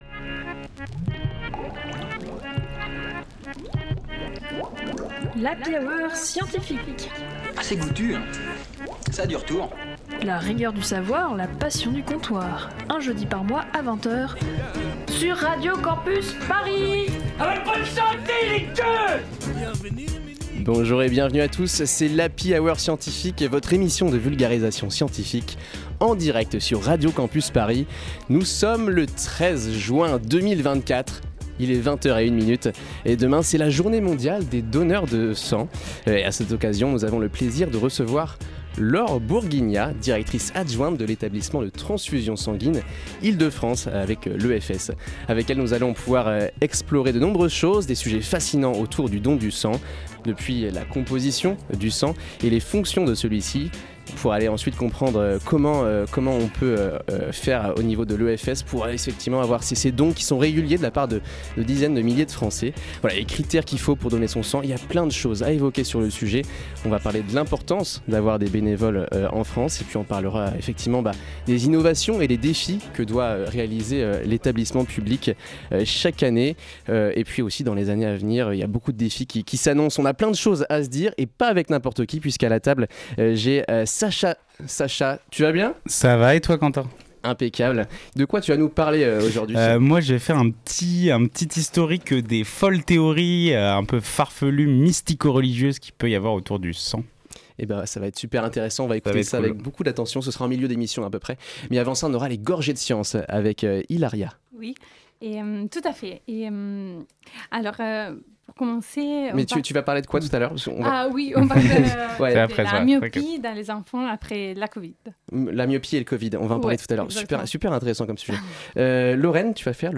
Partager Type Magazine Sciences jeudi 13 juin 2024 Lire Pause Télécharger Le 14 juin marque la Journée mondiale des donneurs de sang.